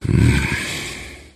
Мужчина голос б вздох 4